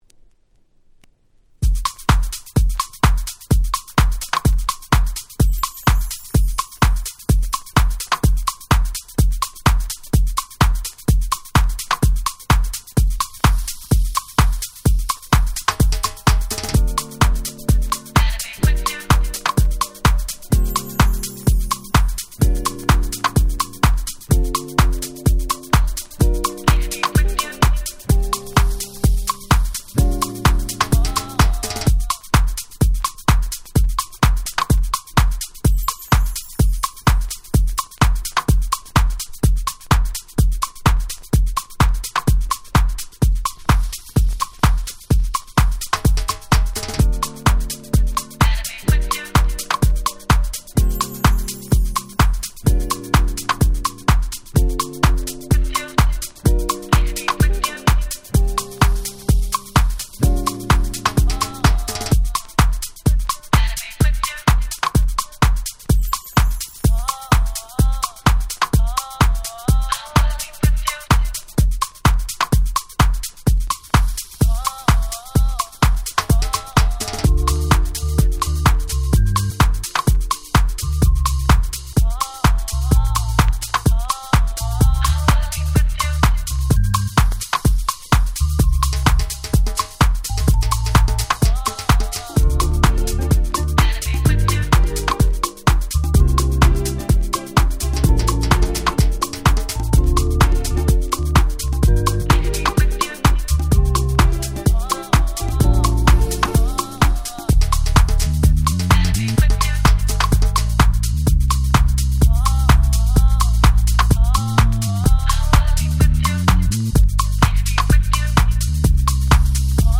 06' Nice House Remix & Hip Hop Soul Remix !!